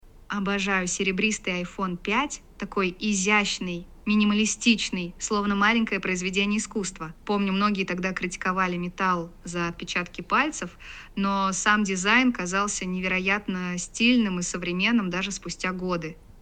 Пример женского голоса
Сейчас есть два голоса, оба звучат очень натурально, молодо и бодро.
Единственное, звучание мужского голоса мне показалось более натуральным, в женском ещё чувствуется синтезированность.
Тест-женский-голос.mp3